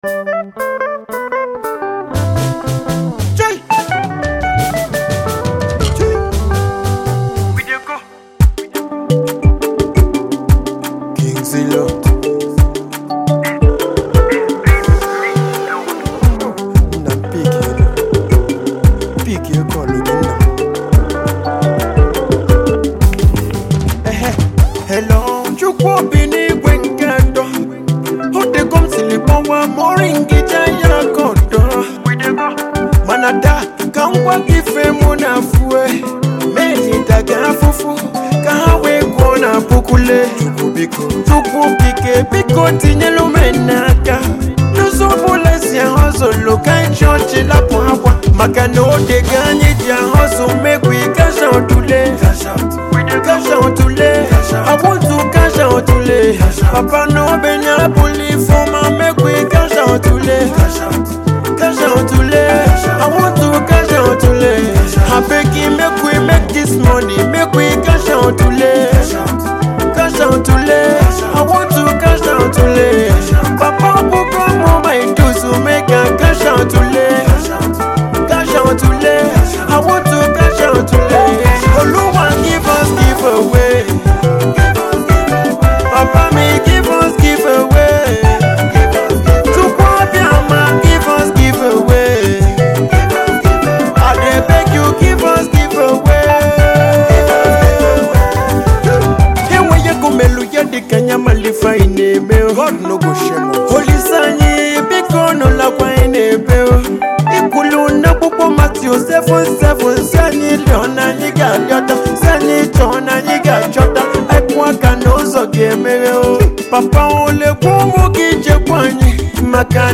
Afro music
a banger